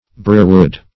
brierwood \bri"er*wood`\ n.